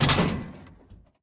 missile_reload.ogg